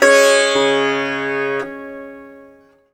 SITAR LINE14.wav